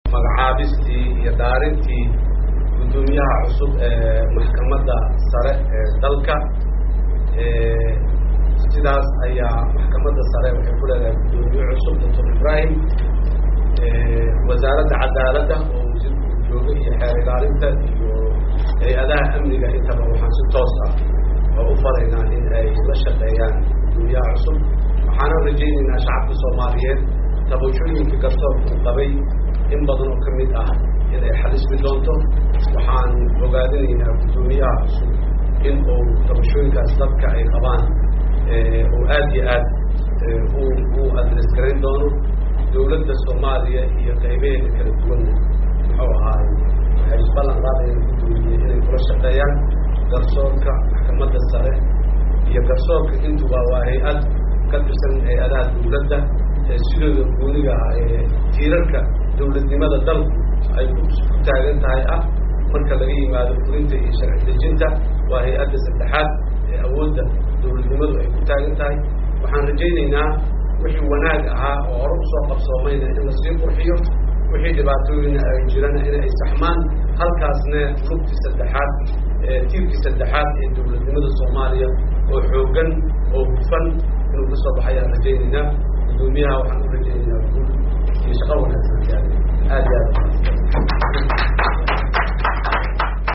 Madaxweyne Xassan Sheekh Maxamud oo ka hadlay xil kaqaadista Ceydiid Ilka Xanaf iyo Magacaabista Dr. Ibraahim Iidle Suleymaan, hoos ka dhageyso.